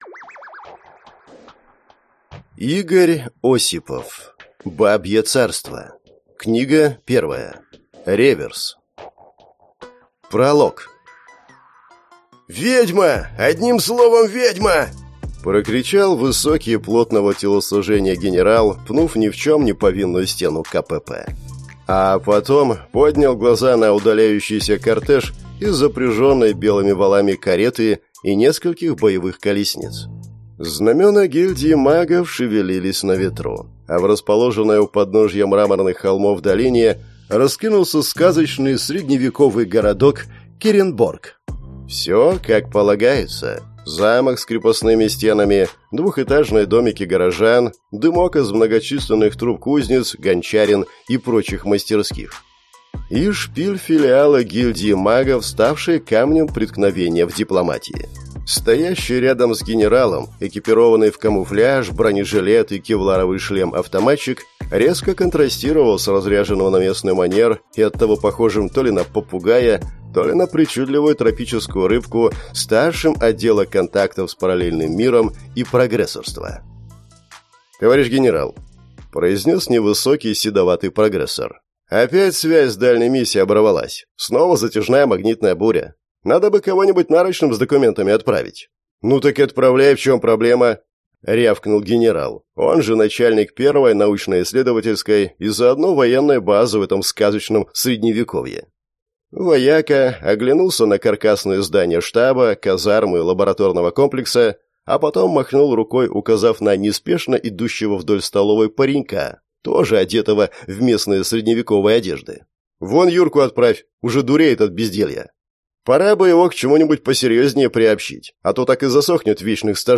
Аудиокнига Реверс | Библиотека аудиокниг